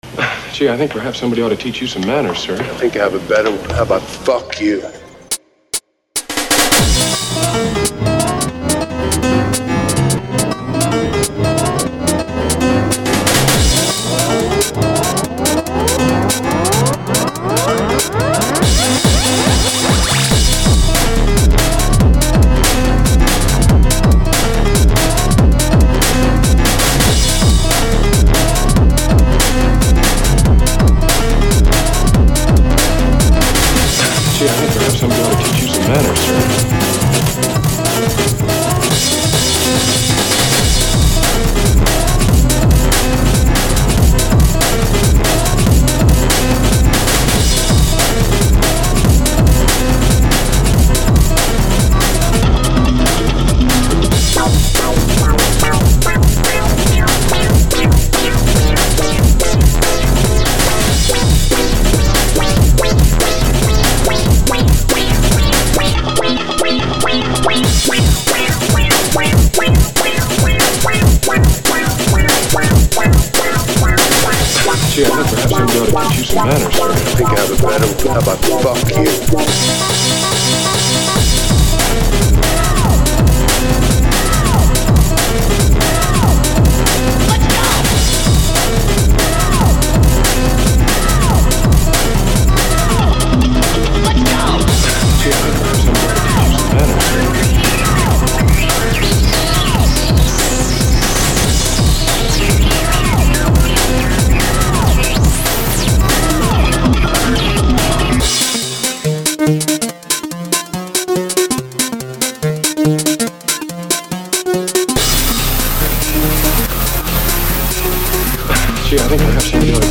This track is kind of weird, in that it's got an assortment of sections that all flow from one and another, but it's kind of detuned in a way i cannot explain. The main bulk is a blend of really jazzy samples and breakbeats, with the break played in a less conventional way, whilst other synth goodies are added to keep it from being repetitive, whilst also being filtered for some funky goodness. It's about being as strange but very funky as possible.